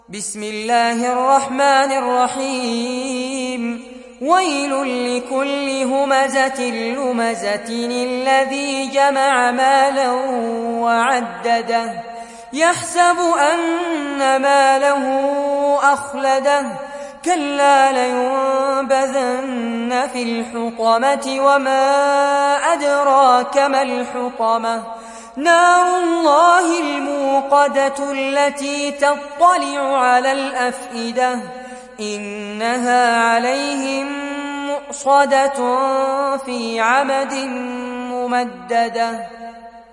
Sourate Al Humaza mp3 Télécharger Fares Abbad (Riwayat Hafs)